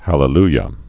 (hălə-lyə)